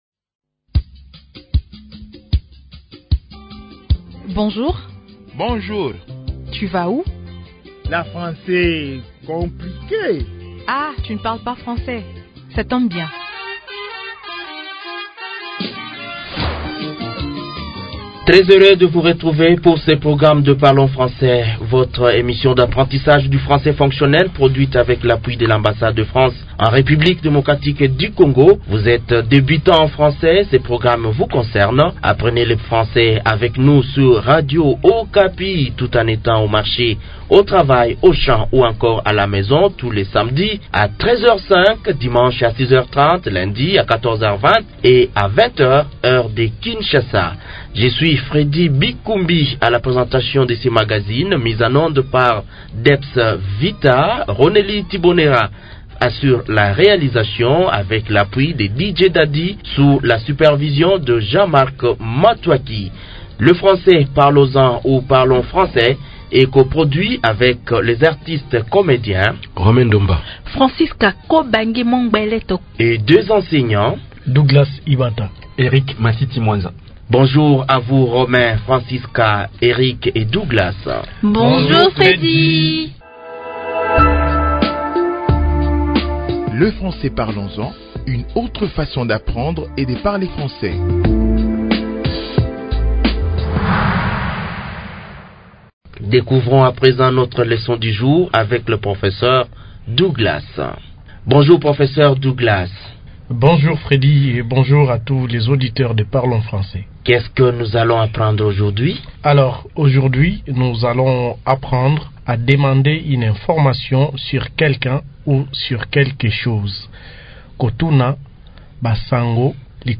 Vous êtes tombé au bon endroit, car cette nouvelle leçon de notre programme répond parfaitement à votre besoin. Dans ce numéro, nous avons le plaisir de vous faire découvrir des expressions simples et pratiques pour demander des informations sur quelque chose.